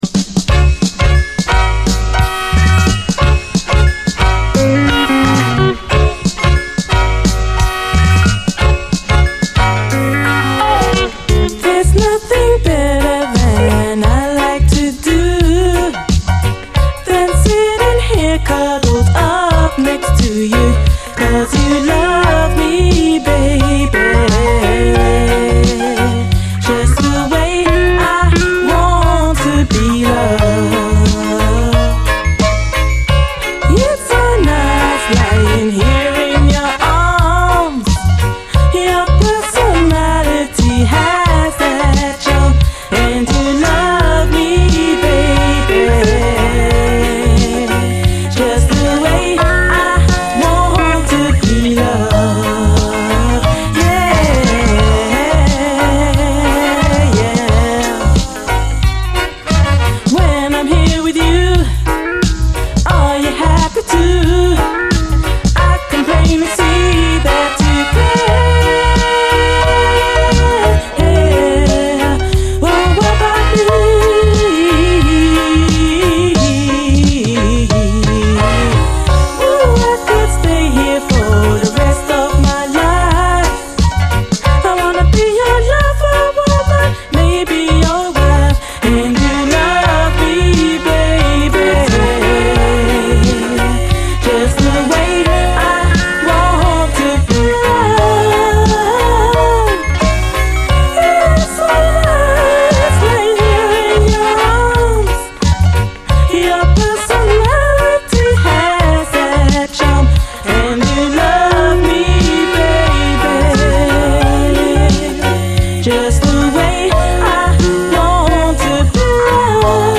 盤見た目スレ結構ありますがプレイは良好です
メロウ・レゲエ・カヴァー